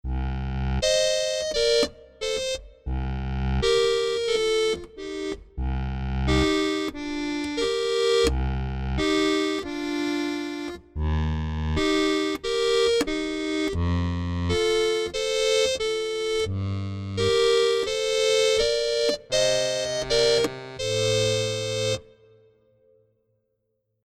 Das Patch Harmochord – Dry Slow bildet dem Höreindruck (und dem Namen nach) die Nahmikrofonierung ab und klingt absolut trocken, also ohne einen nachschwingenden Raumklang.
Der vollvolumige Klang des Instruments mit viel Körper, beinahe greifbarer physischer Präsenz, ist eine weitere Meisterleistung, von denen man so viele in Keyscape findet.
Zu dessen Authentizität tragen nicht zuletzt die Nebengeräusche bei, insbesondere das Release-Geräusch, welches je nach Taste sehr unterschiedlich ausfällt.